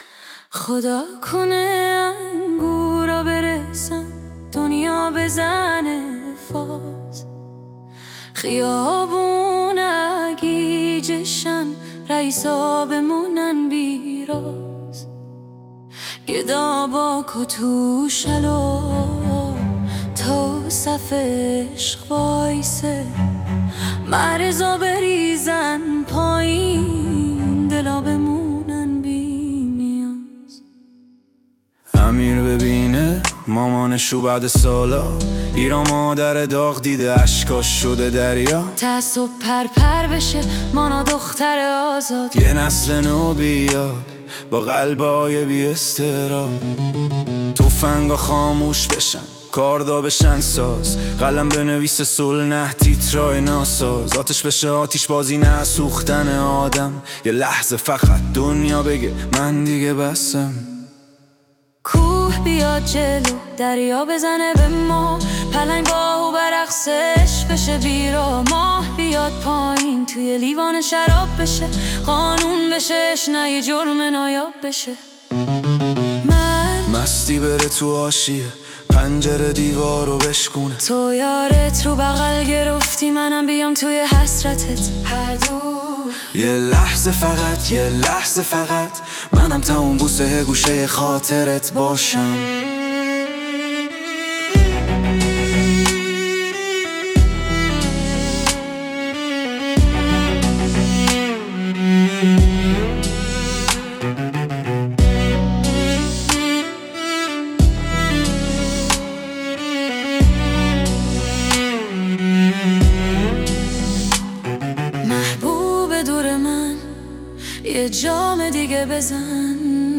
Persian Fusion Tribute
Instruments: Bass, Santoor, Hang Drum, Cello 🎧
Vocals: Male & Female 🎙
Genre: Persian Pop Hip-Hop ⚡